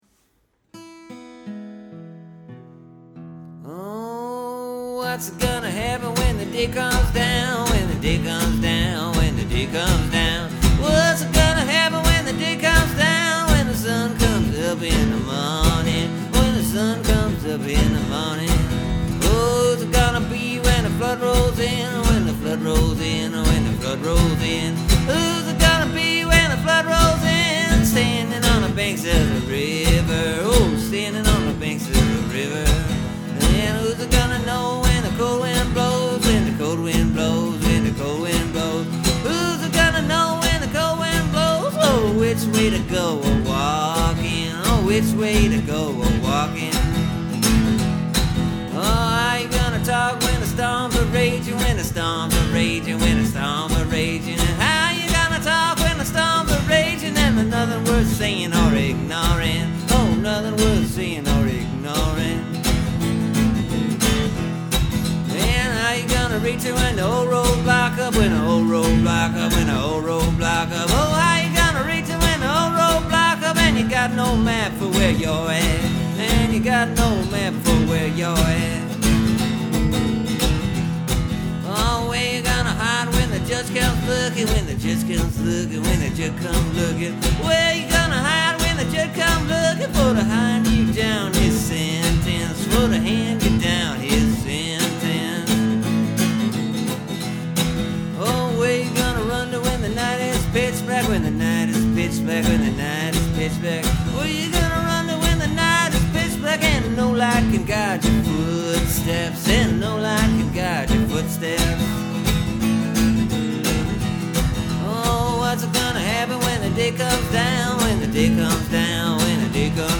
It’s a simple easy little tune. Moves right along in a rumbling sort of way.